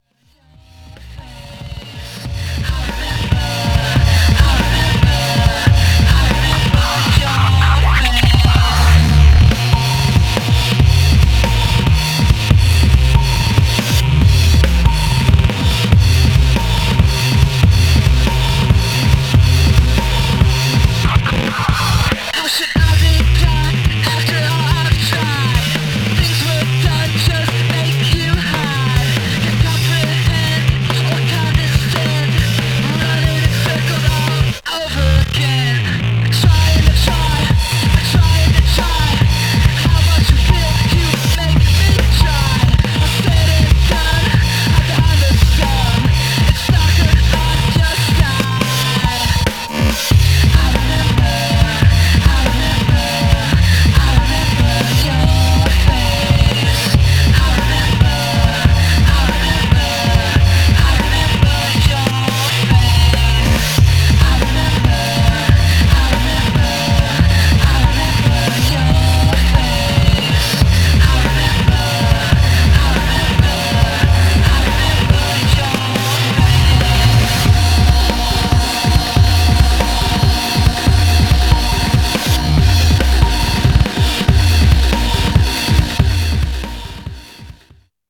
Styl: Techno, Breaks/Breakbeat